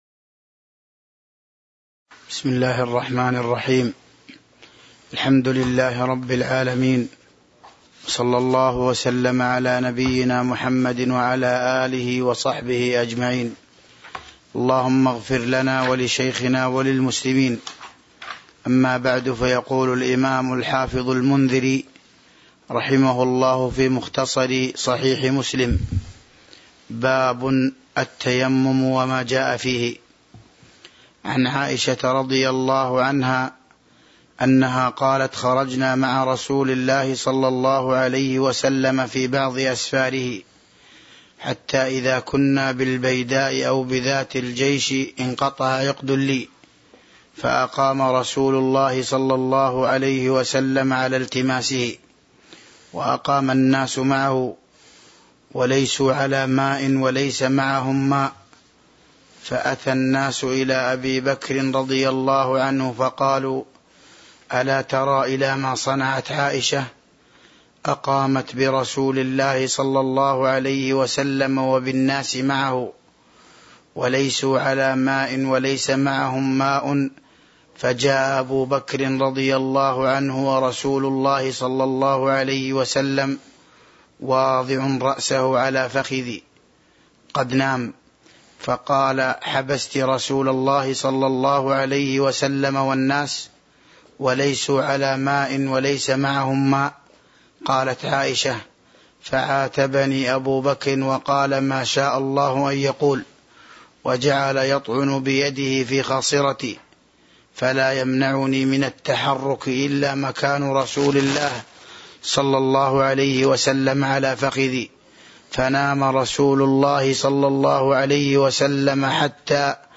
تاريخ النشر ٧ ربيع الثاني ١٤٤٢ هـ المكان: المسجد النبوي الشيخ